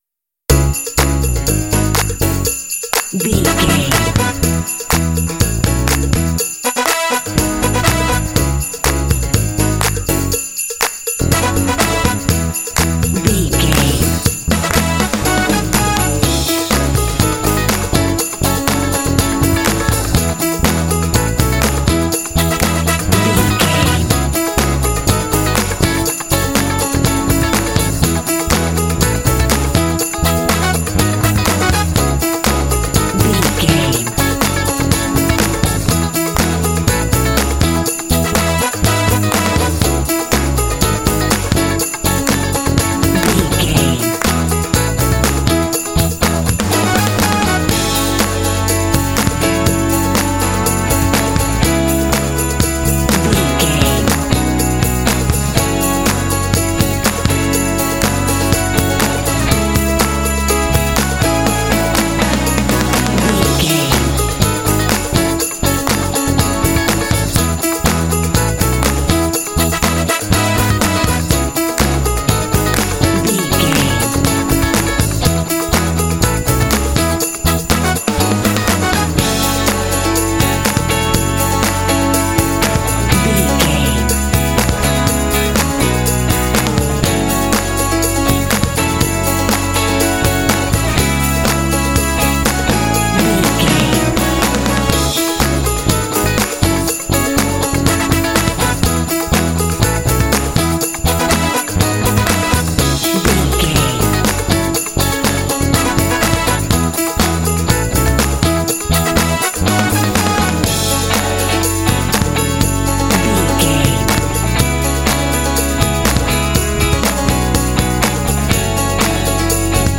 Ionian/Major
joyful
bouncy
festive
drums
brass
piano
bass guitar
electric guitar
contemporary underscore